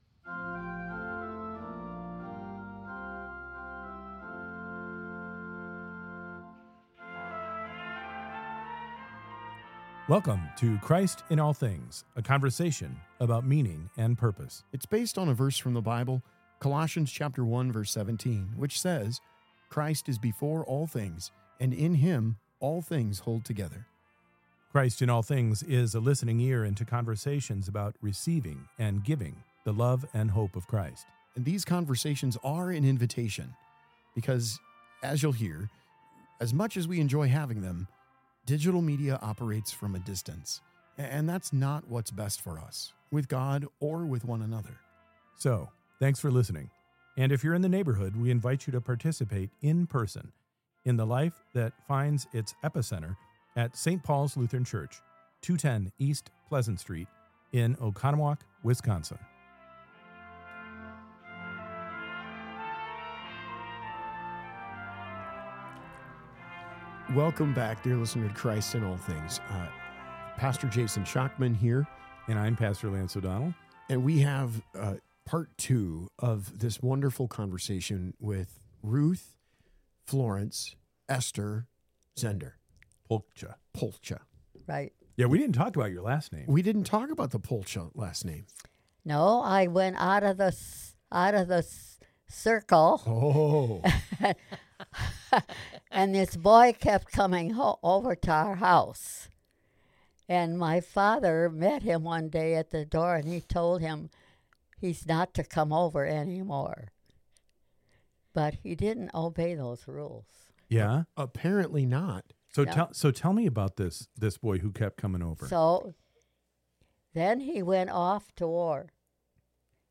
We have to *bleep some things now and then, and we roll our eyes at each other now and then, but when you listen to Christ In All Things, you’ll find common people who rejoice in the uncommon moments animated by the Gospel of Jesus Christ.